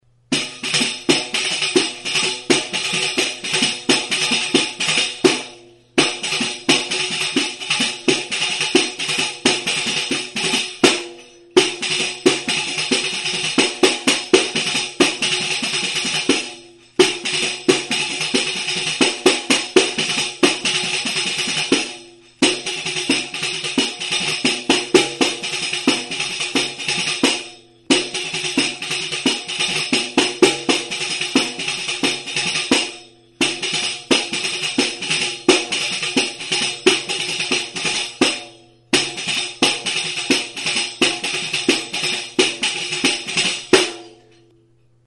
Music instrumentsPANDEIRETA; PANDERO
Membranophones -> Beaten -> Tambourines
Recorded with this music instrument.
Zurezko uztai zilindrikoa du. Alde batean tinkaturitako larruzko mintza du. Bi lerrotan jarririk, metalezko 11 txinda pare ditu.